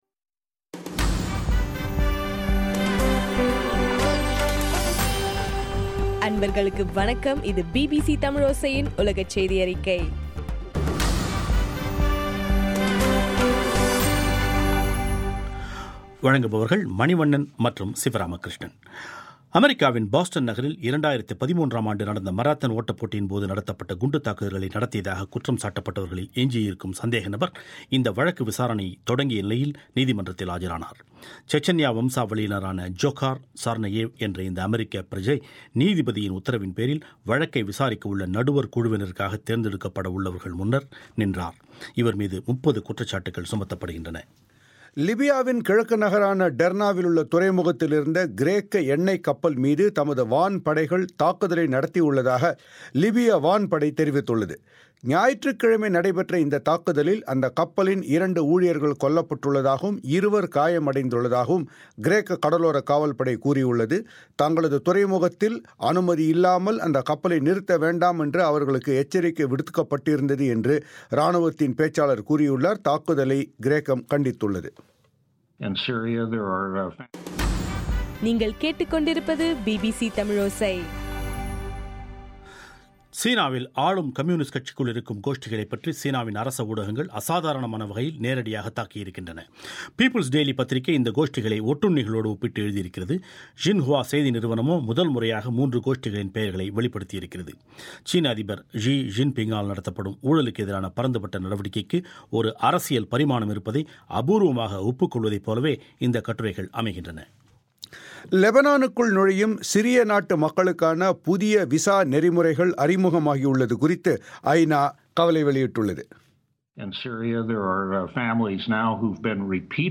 பிபிசி தமிழோசை உலகச் செய்தியறிக்கை- ஜனவரி 5